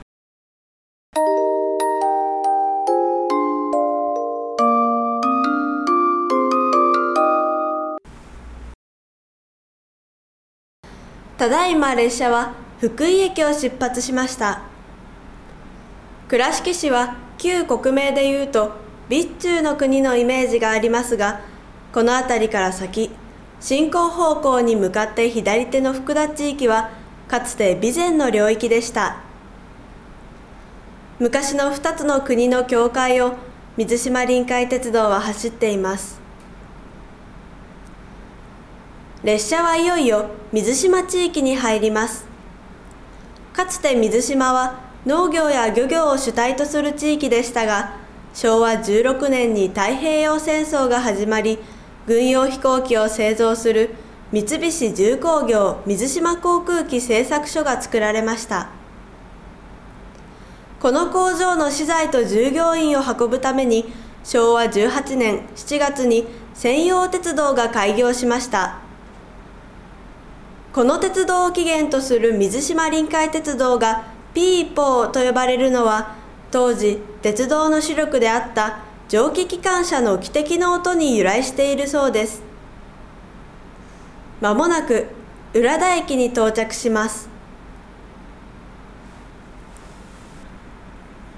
水島地域の魅力を発信するため、水島臨海鉄道（ピーポー）から目にできる風景や特産物、地域の歴史などを音声で紹介する「ピーポー車窓ガイド」を制作しました。